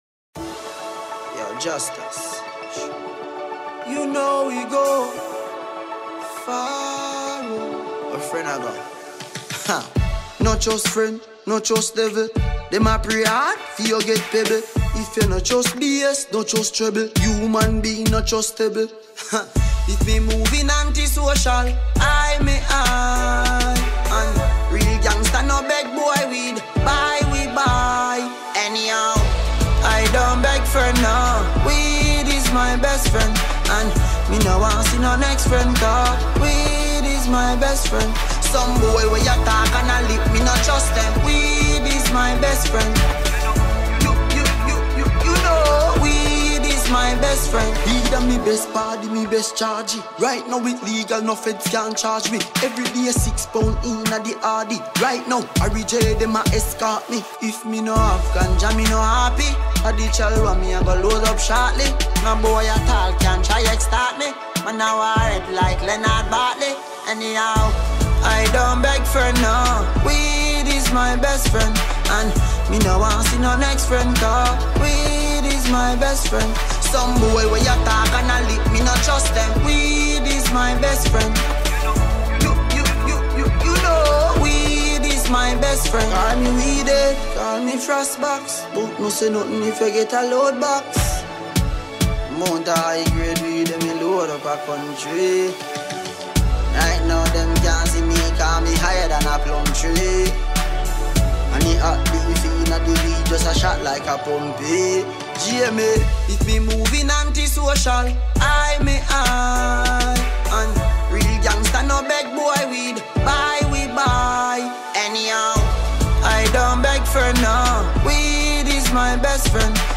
we present to you this old song from Jamaican dancehall act